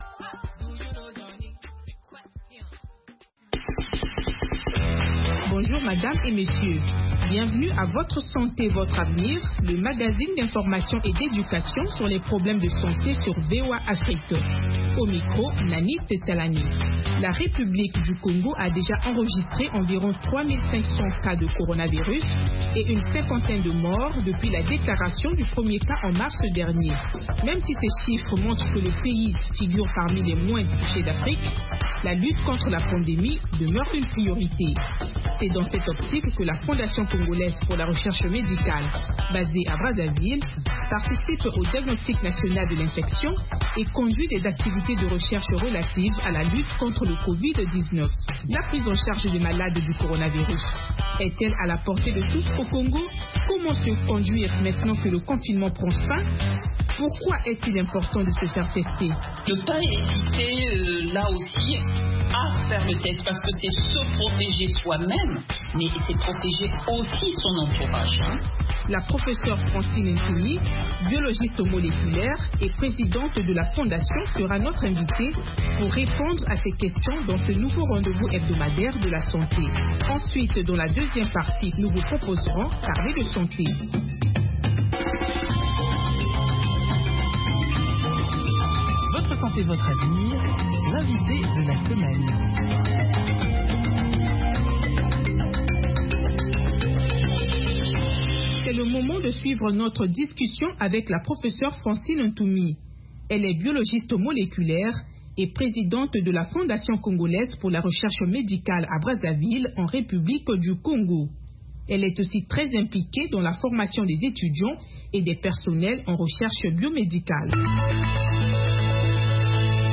Fistules etc. Avec les reportages de nos correspondants en Afrique. VOA donne la parole aux personnes affectées, aux médecins, aux expert, aux parents de personnes atteintes ainsi qu’aux auditeurs.